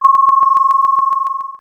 Ringing03.wav